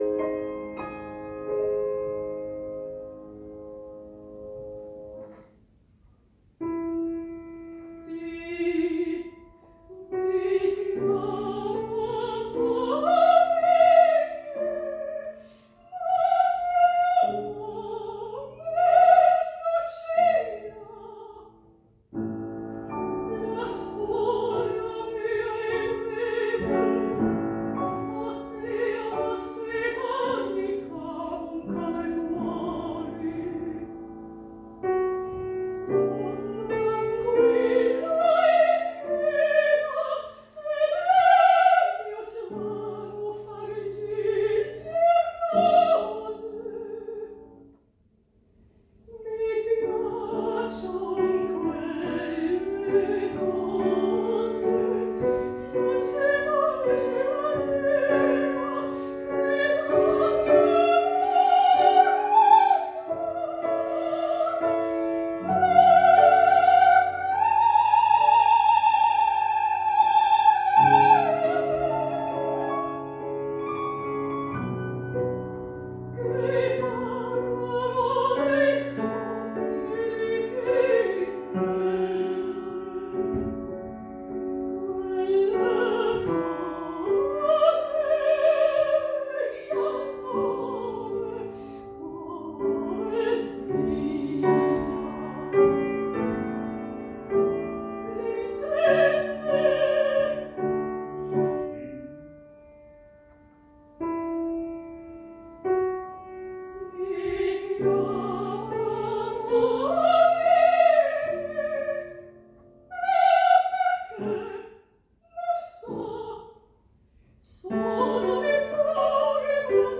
Oper/Lied